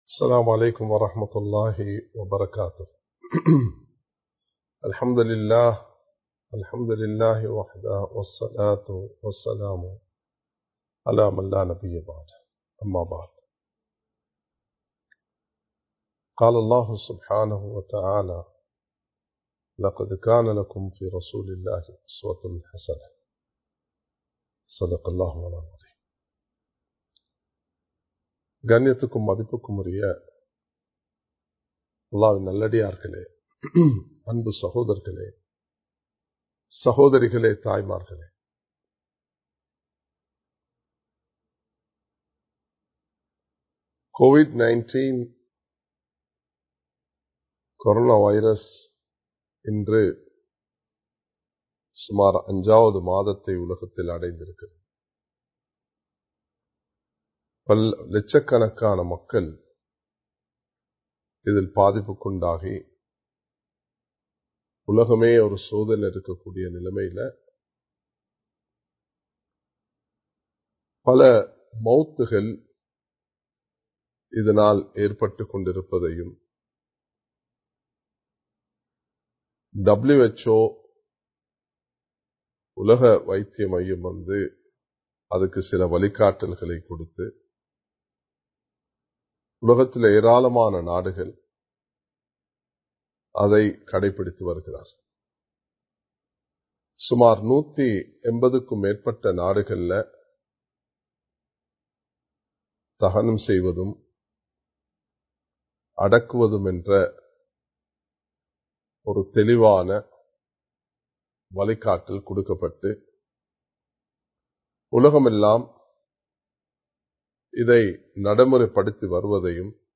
ஜனாஸாவை எரிக்க முடியுமா? | Audio Bayans | All Ceylon Muslim Youth Community | Addalaichenai
Live Stream